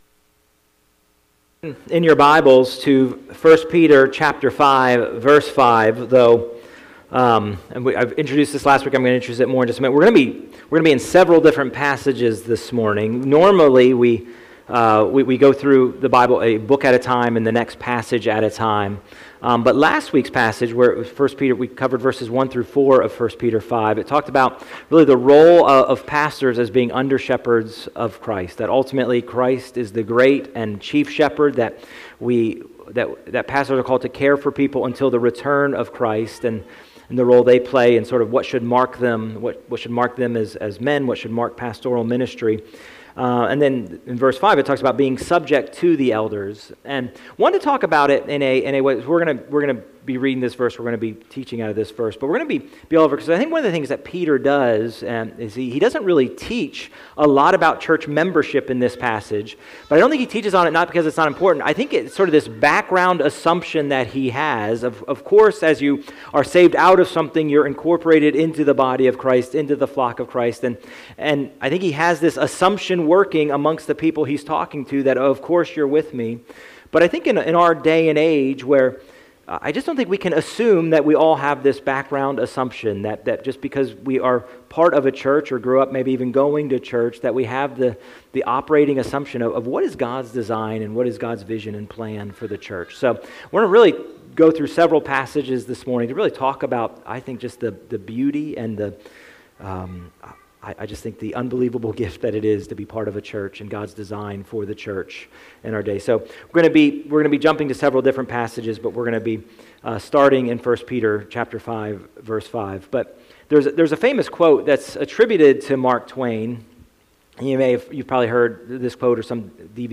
A message from the series "Higher." From 1 Peter 5:1-5 we see that God's higher purpose in His local church. 1.